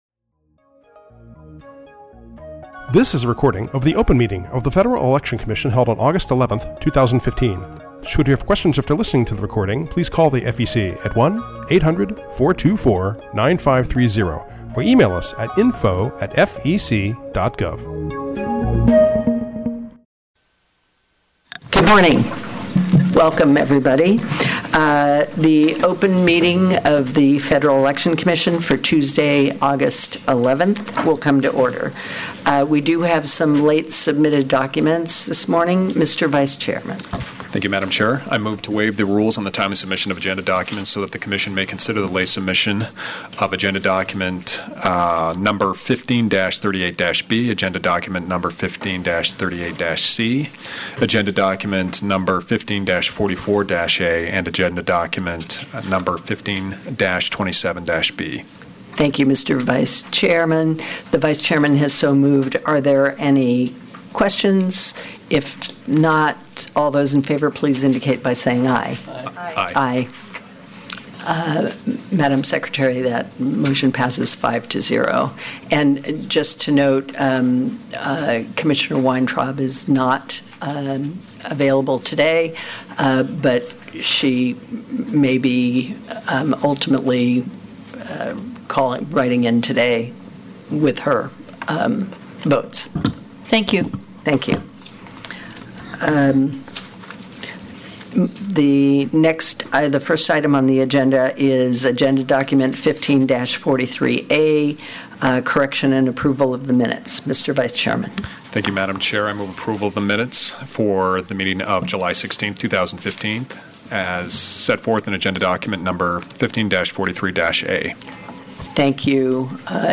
August 11, 2015 open meeting | FEC
Audio File of Entire Meeting